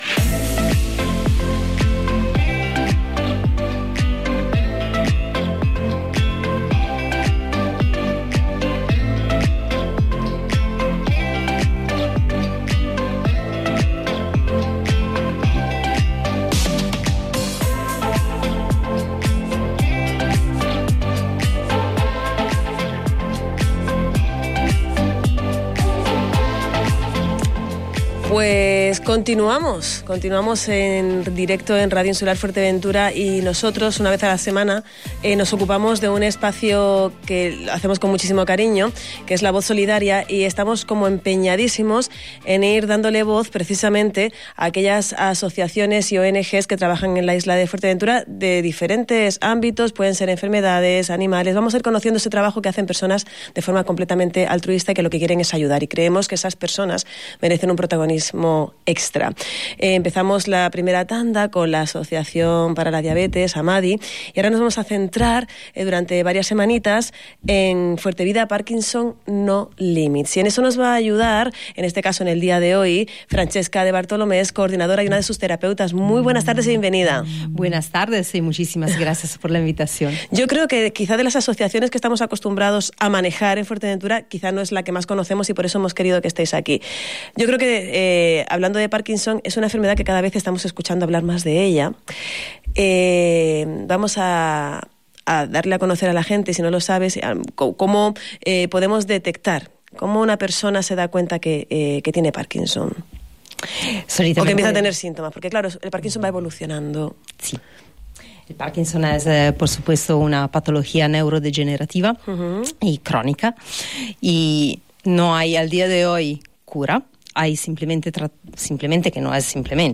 Desde la Asociación FuerteVida Parkinson No Limits nos cuentan todo sobre su actividad en el espacio La Voz Solidaria del programa El Tardeo en Radio Insular